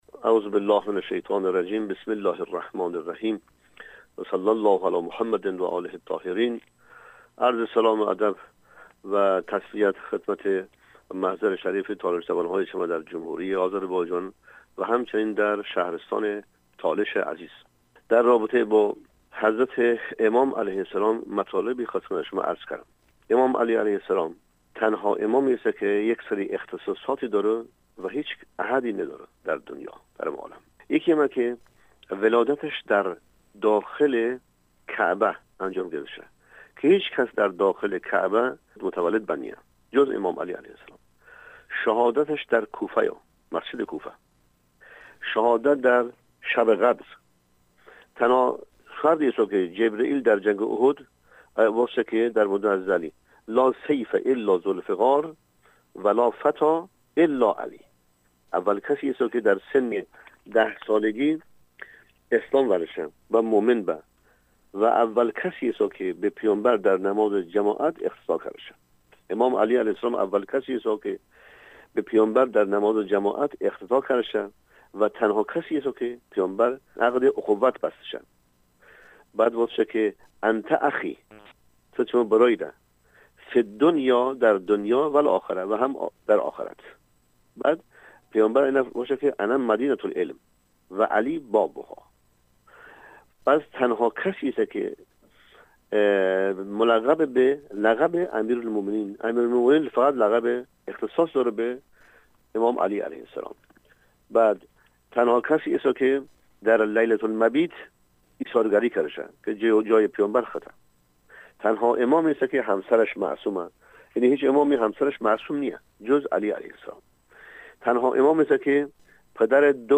tolışə ruhani